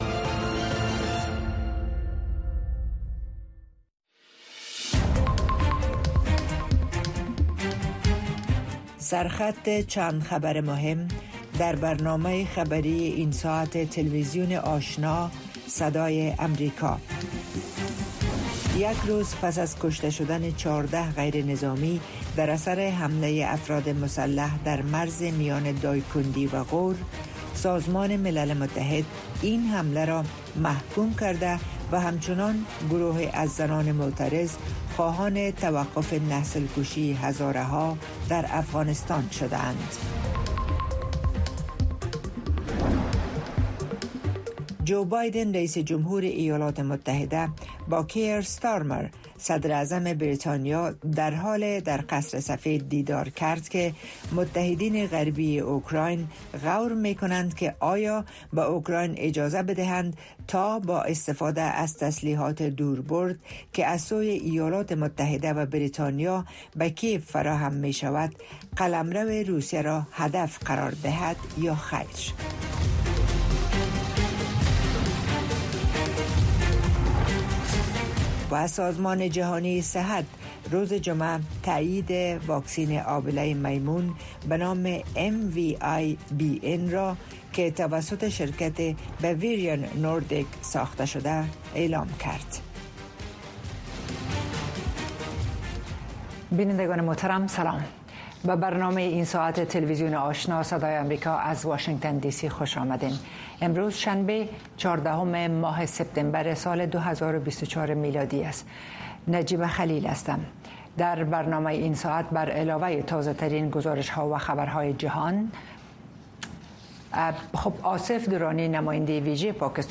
تازه‌ترین خبرهای افغانستان، منطقه و جهان، گزارش‌های جالب و معلوماتی از سراسر جهان، مصاحبه‌های مسوولان و صاحب‌نظران، صدای شما و سایر مطالب را در برنامهٔ خبری آشنا از روزهای شنبه تا پنج‌شنبه در رادیو، ماهواره و شبکه های دیجیتلی صدای امریکا دنبال کنید.